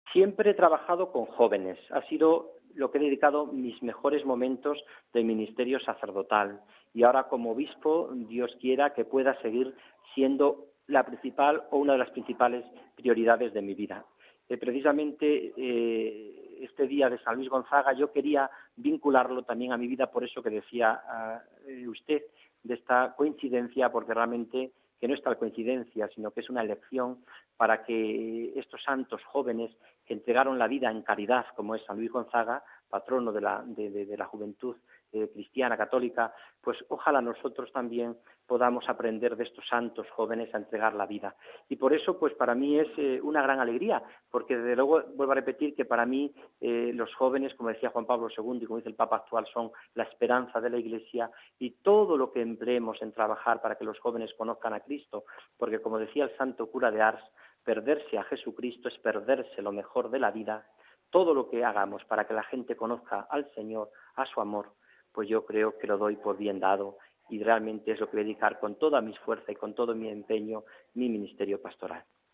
Y, teniendo la oportunidad de conversar con él, le damos la enhorabuena y le pedimos que nos cuente qué siente ante este nuevo encargo: RealAudioMP3